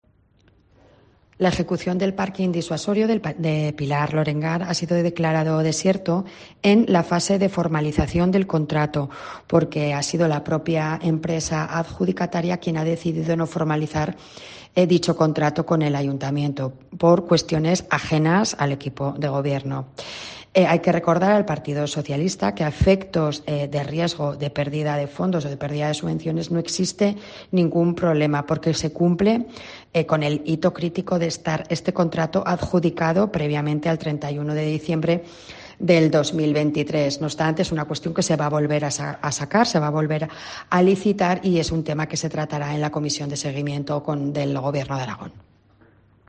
La responsable de Seguridad Ciudadana, Gema Allué, habla del aparcamiento de Pilar Lorengar